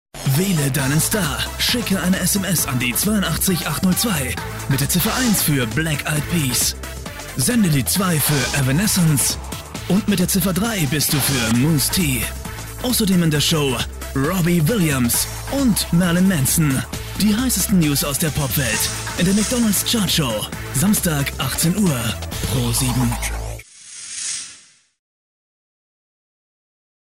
deutscher Sprecher
Kein Dialekt
Sprechprobe: eLearning (Muttersprache):
voice over artist german